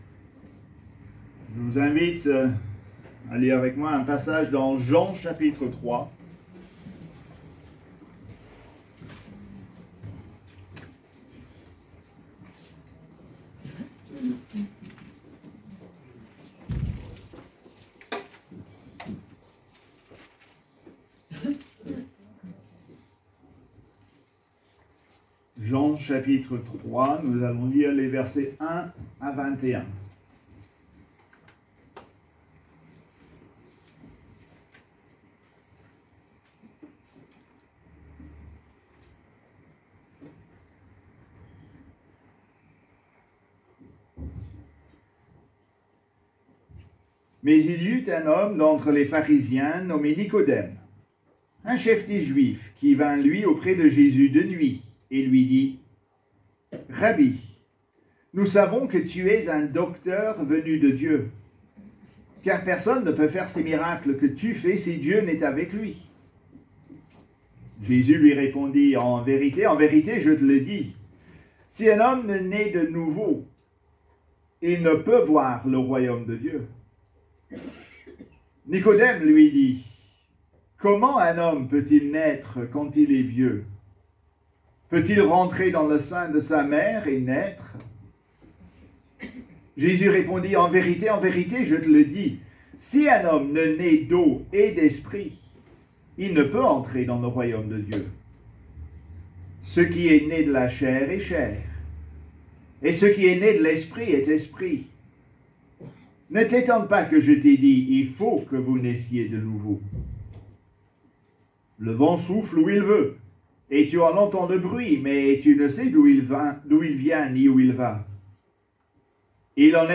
Prédication du 12 octobre☺2025